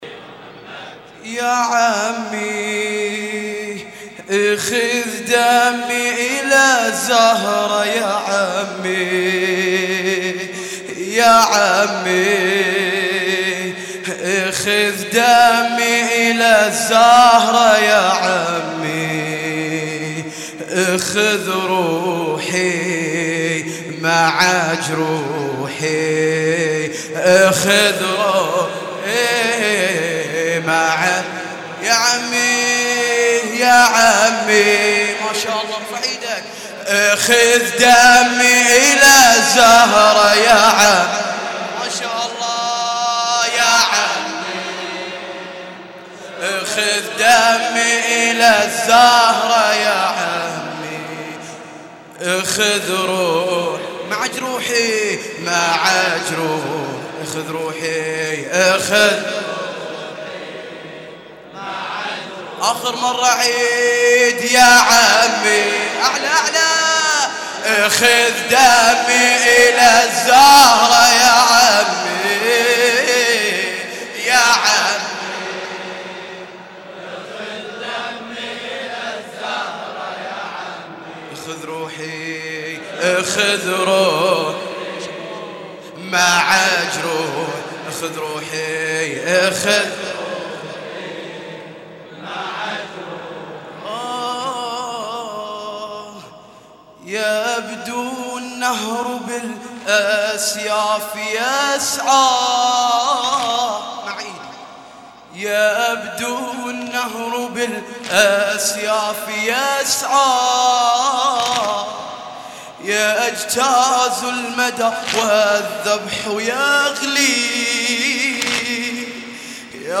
لطمية الليلـ 08 ـة الجزء (02)